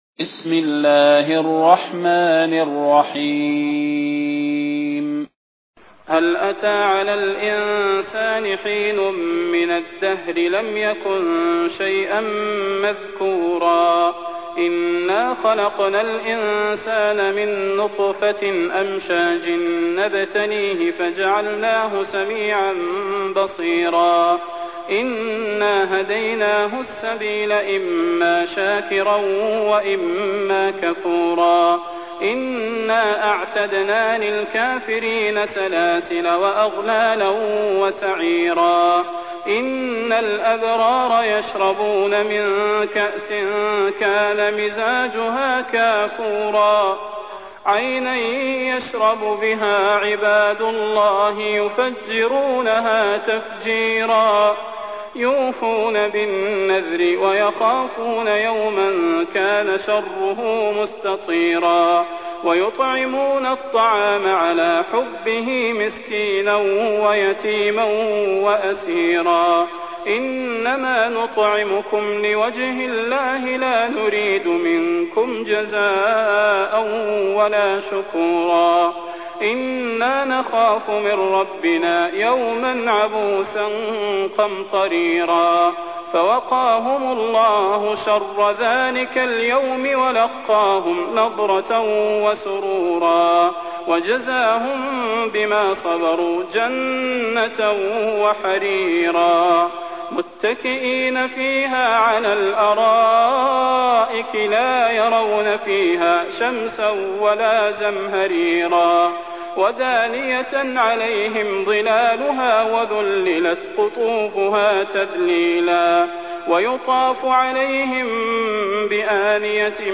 المكان: المسجد النبوي الشيخ: فضيلة الشيخ د. صلاح بن محمد البدير فضيلة الشيخ د. صلاح بن محمد البدير الإنسان